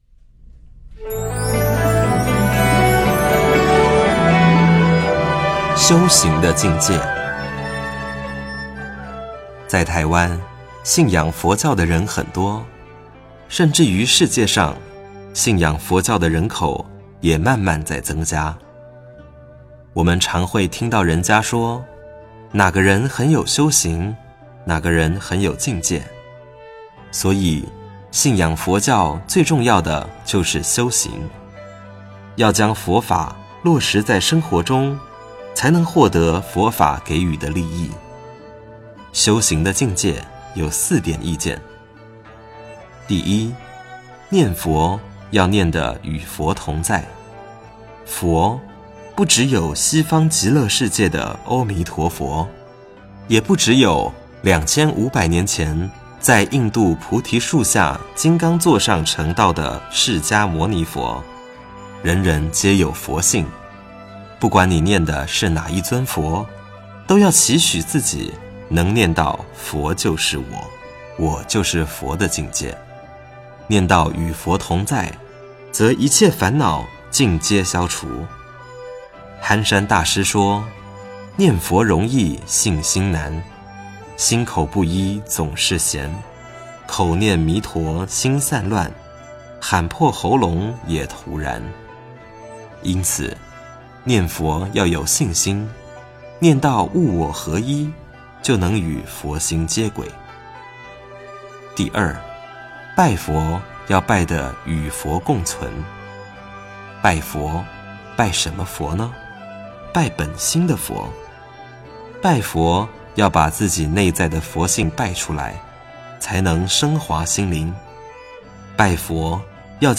标签: 佛音冥想佛教音乐